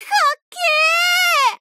贡献 ） 协议：Copyright，其他分类： 分类:伏特加(赛马娘 Pretty Derby)语音 您不可以覆盖此文件。